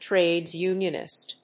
アクセントtráde(s) únionist
trades+unionist.mp3